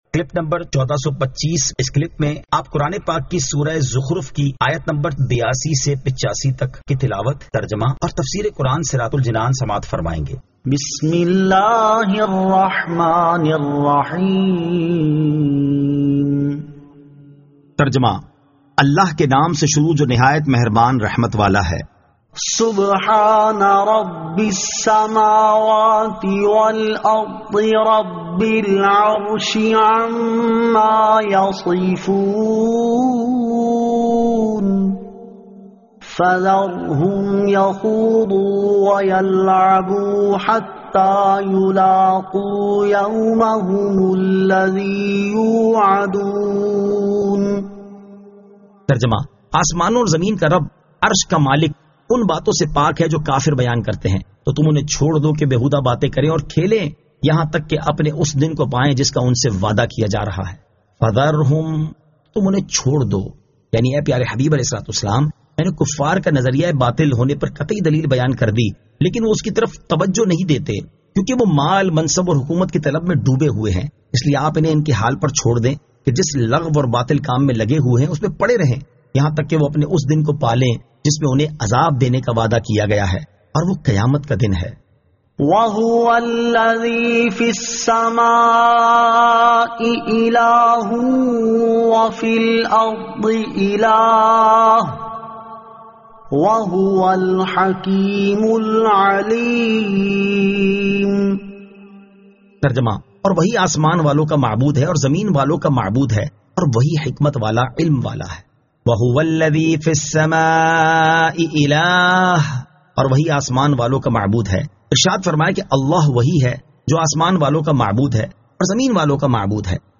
Surah Az-Zukhruf 82 To 85 Tilawat , Tarjama , Tafseer